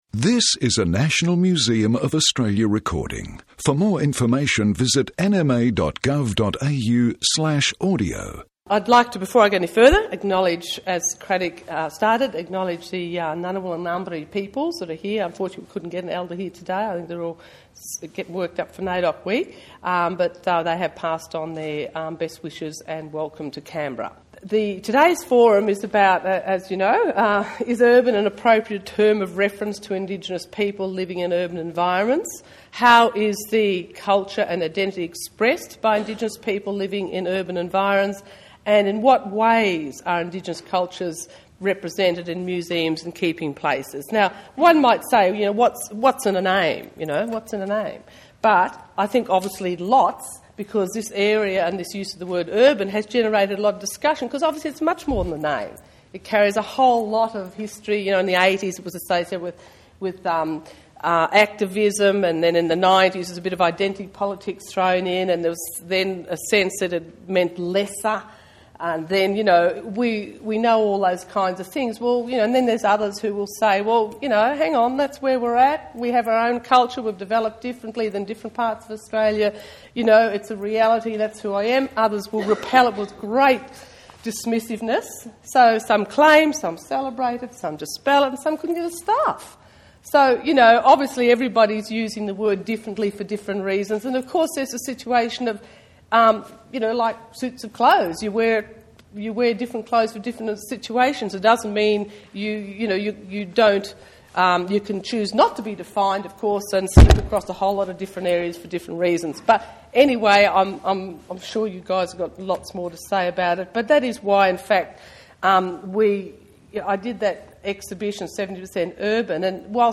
Who You Callin’ Urban? forum 06 Jul 2007
Open player in a new tab Presenters: Vernon Ah Kee, Bronwyn Bancroft, Richard Bell, Wesley Enoch and Anita Heiss Tags: first nations 00:00 / 153:05 Download Who you callin’ urban? audio file (70.3 MB) View transcript